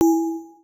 Universal UI SFX / Clicks
UIClick_Tonal Resonance Button 01.wav